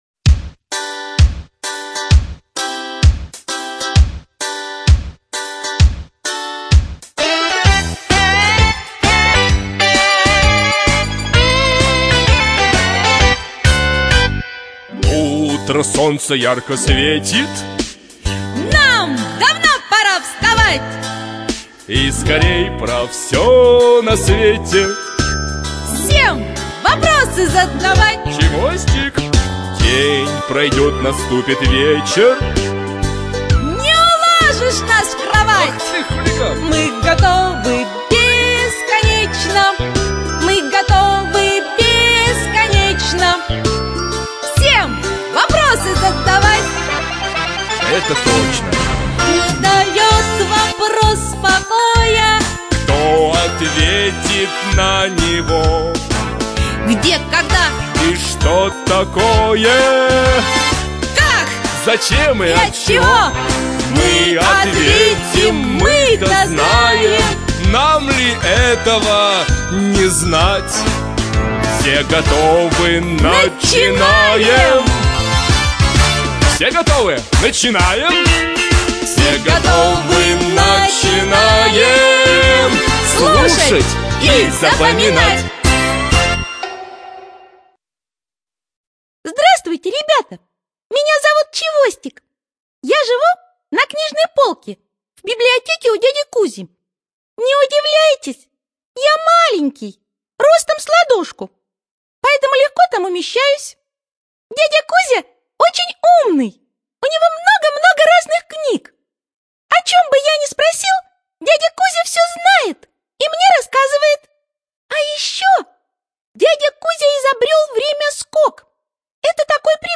АвторАудиоэнциклопедия для детей
ЖанрДетская литература, Наука и образование